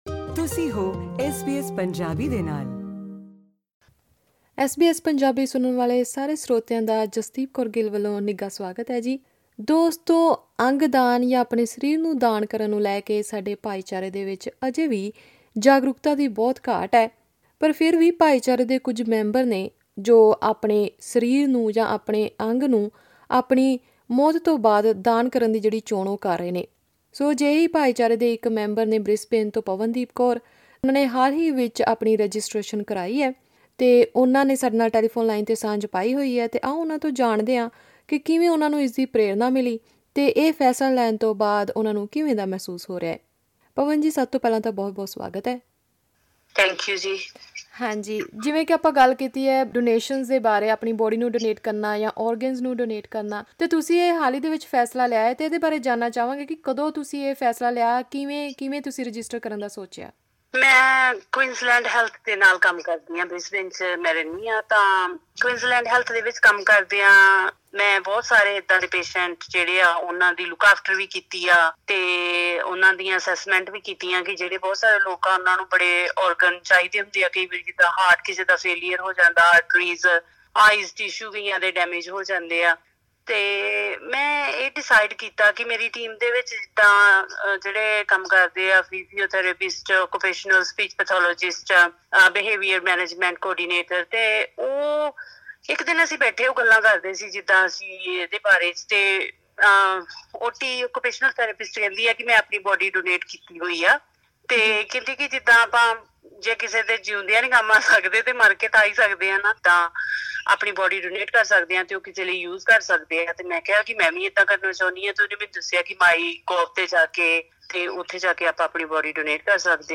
ਉਹਨਾਂ ਐਸ ਬੀ ਐਸ ਪੰਜਾਬੀ ਨਾਲ ਗੱਲਬਾਤ ਕਰਦਿਆਂ ਦੱਸਿਆ ਕਿ ਖੁਦ ਨੂੰ ਅੰਗਦਾਨ ਲਈ ਰਜਿਸਟਰ ਕਰਨ ਤੋਂ ਬਾਅਦ ਉਹਨਾਂ ਨੂੰ ਮਹਿਸੂਸ ਹੋਇਆ ਕਿ ਭਾਈਚਾਰੇ ਵਿੱਚ ਇਸ ਸਬੰਧੀ ਜਾਗਰੂਕਤਾ ਹੋਣੀ ਚਾਹੀਦੀ ਹੈ। ਉਹਨਾਂ ਭਾਈਚਾਰੇ ਨੂੰ ਰਜਿਸਟਰ ਕਰਨ ਦਾ ਸੁਨੇਹਾ ਦਿੰਦੇ ਹੋਏ ਇਸ ਦੀ ਪ੍ਰੀਕਿਰਿਆ ਬਾਰੇ ਵੀ ਜਾਣਕਾਰੀ ਸਾਂਝੀ ਕੀਤੀ।